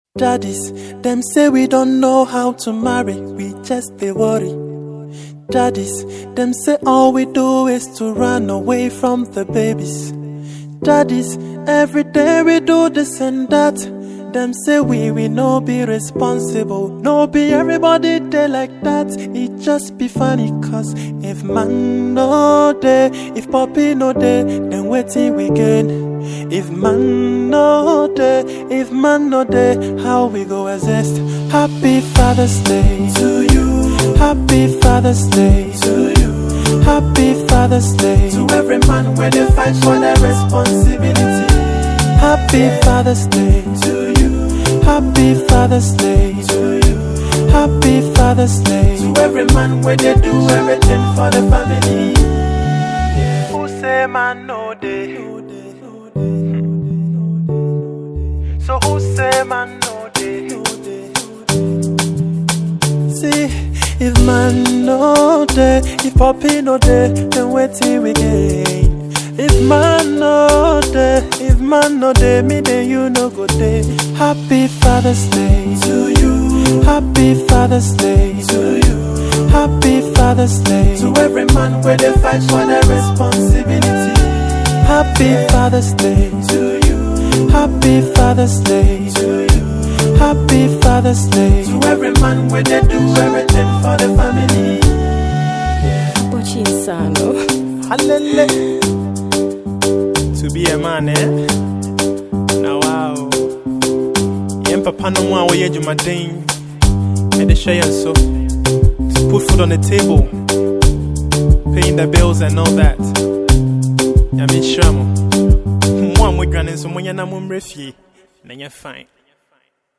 This is a celebration melody that’s worthy of appreciation.